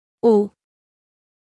File:Phoneme (Commonscript) (Accent 0) (6) (Female).mp3
Audio of the phoneme for Commonscript letter 6 (pronounced by female).